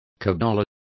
Complete with pronunciation of the translation of cabala.